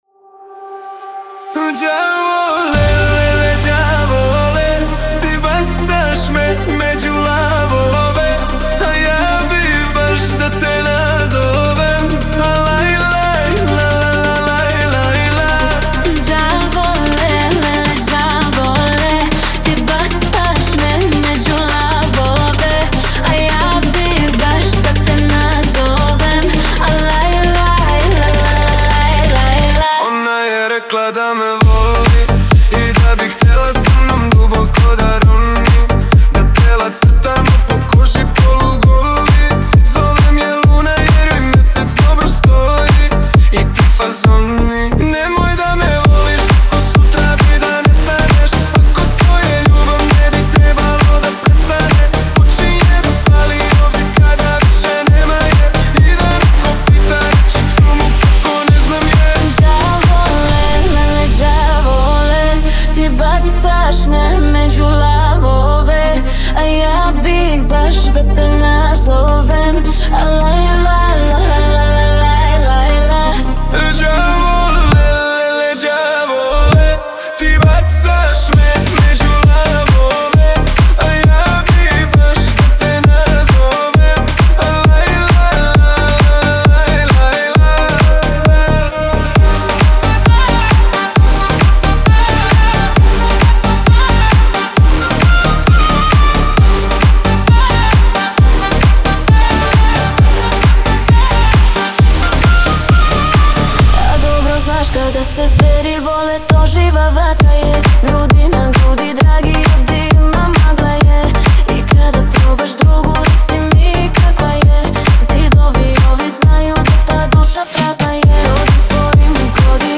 REMIXES - MP3 FILES